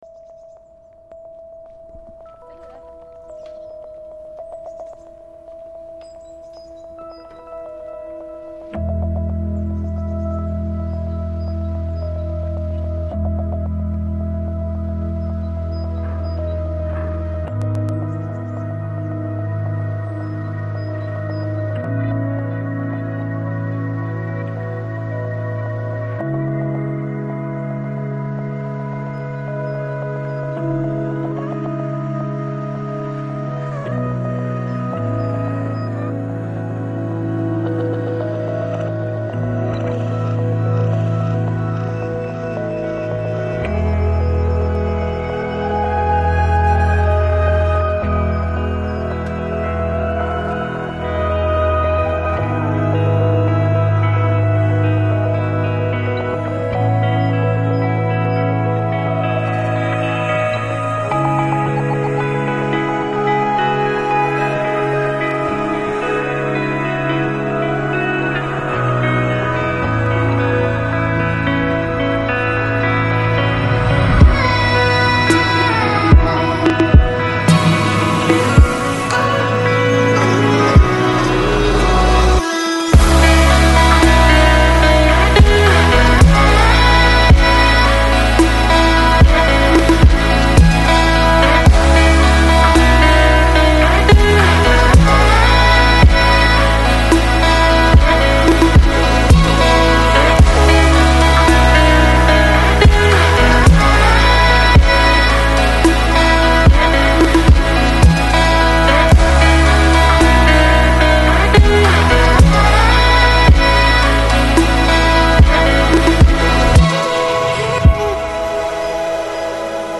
Жанр: Downtempo / Chillstep